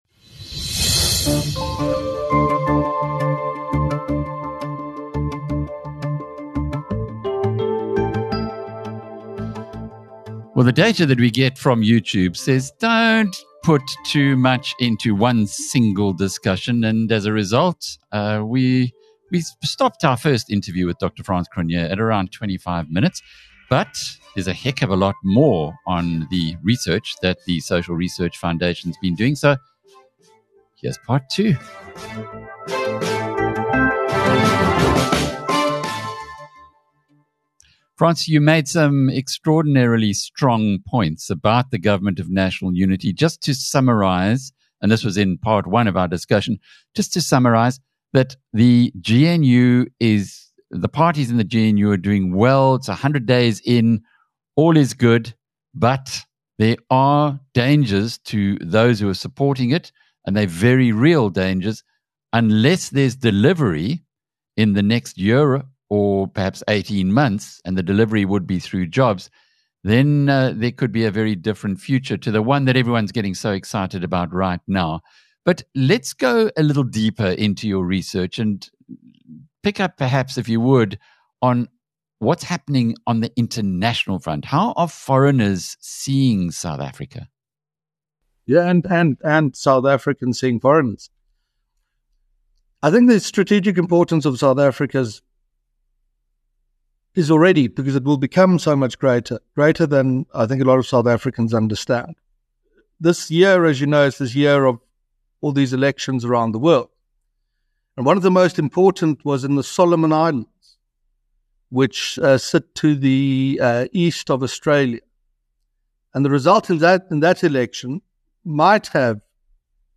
In a detailed interview